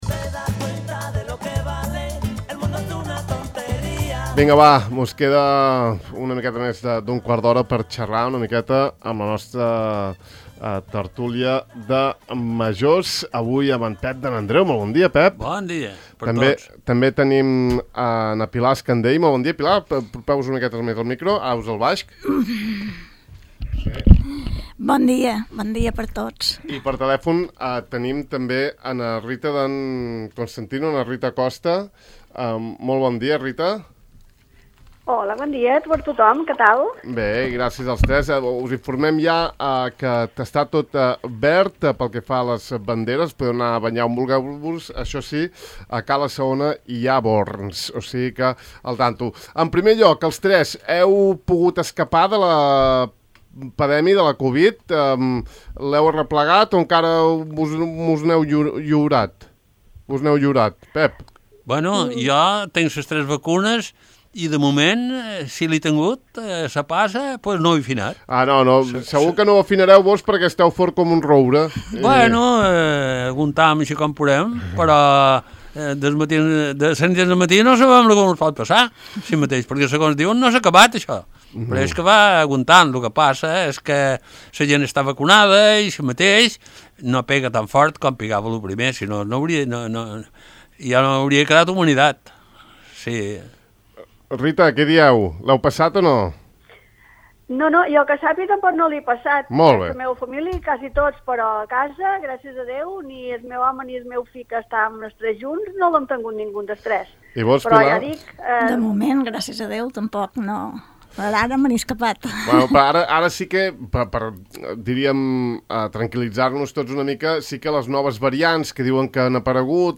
Avui hem gaudit d’un dels plaers del que més gaudim els professionals de la ràdio de Formentera i que més ens agrada oferir a la ciutadania de Formentera: una estona de conversa amb majors de l’illa.